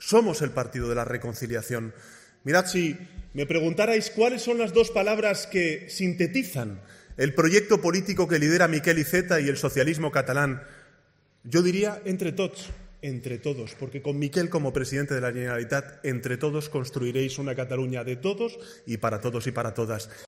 Sánchez, el expresidente del Gobierno José Luis Rodríguez Zapatero y el expresidente del Parlamento Europeo Josep Borrell han arropado a Iceta en el mitin central de los socialistas en Barcelona, ante más de 5.000 personas en el Centre de Convencions Internacional de Barcelona.